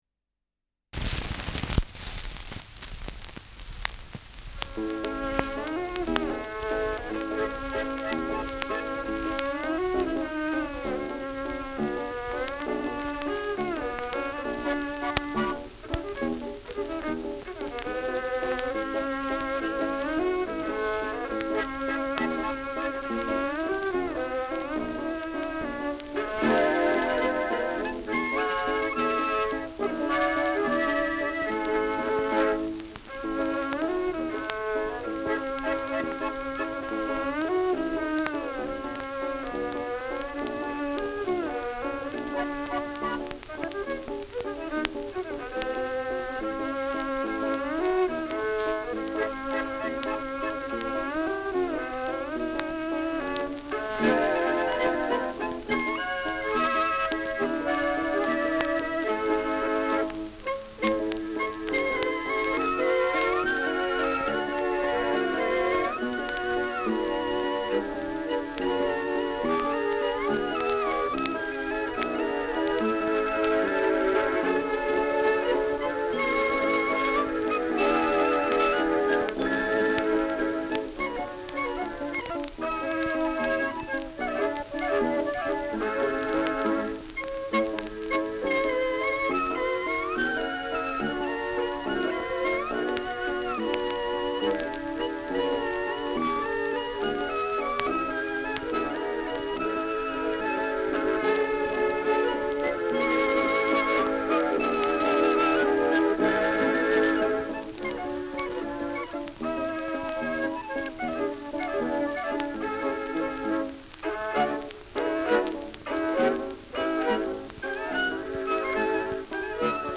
Schellackplatte
Stärkeres Grundrauschen : Durchgehend leichtes bis stärkeres Knacken : Leiern
Original Lanner-Quartett (Interpretation)